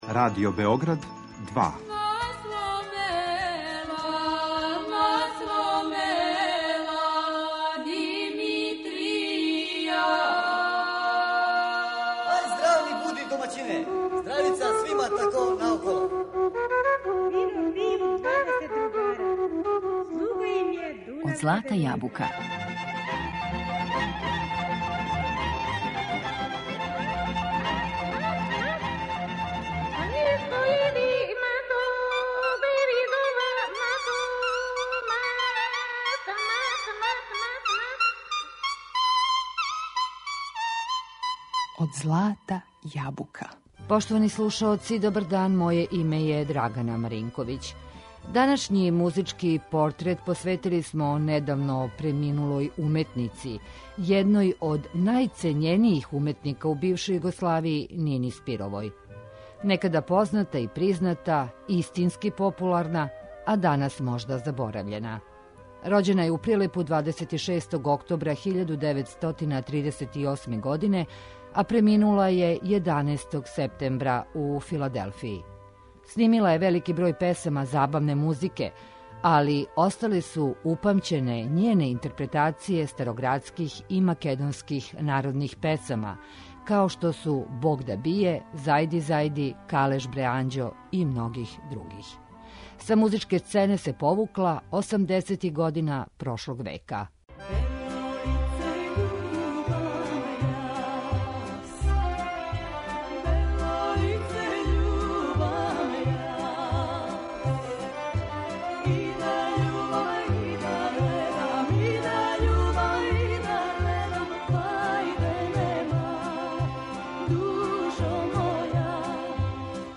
muzički portret
starogradskih i tradicionalnih makedonskih pesama